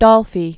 (dŏlfē), Eric Allan 1928-1964.